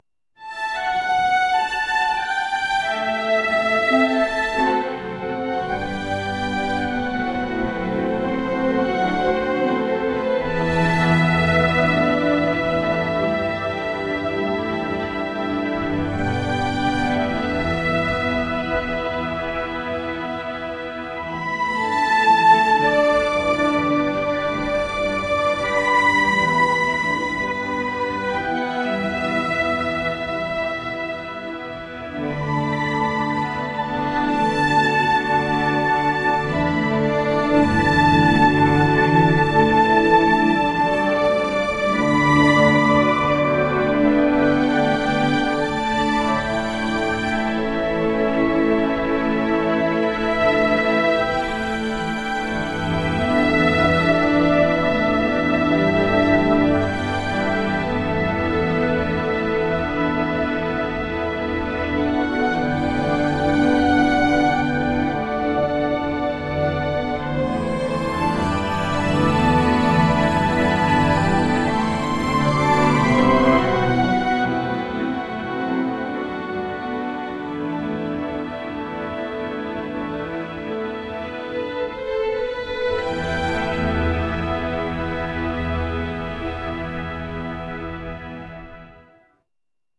「大人な雰囲気」